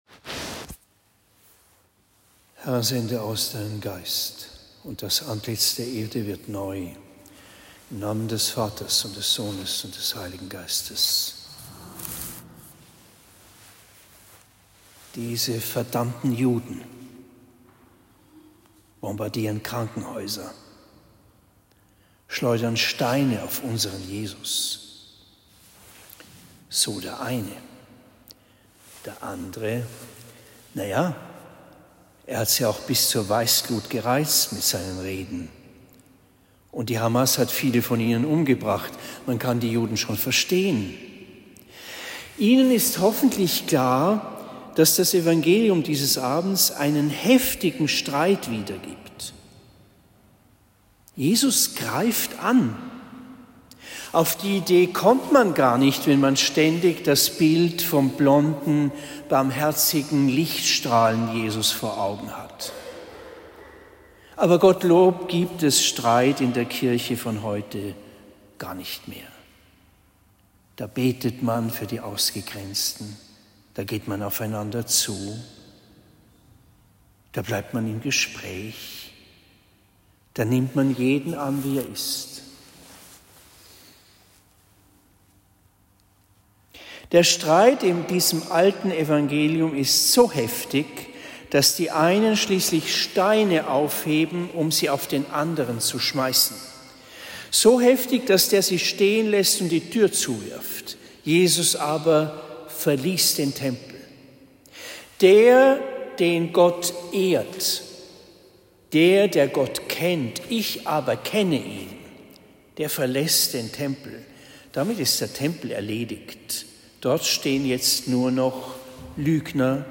Predigt in Marktheidenfeld St.-Laurentius am 21. März 2024